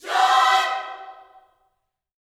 JOY CHORD1.wav